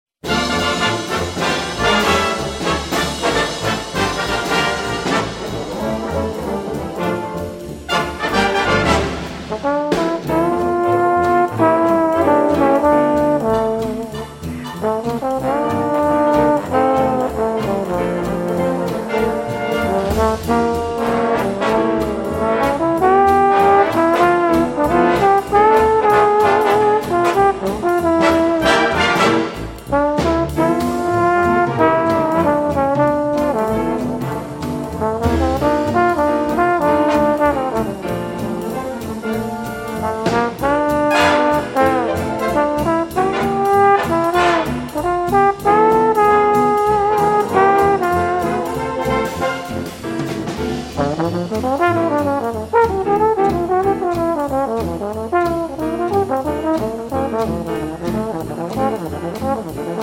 Trombone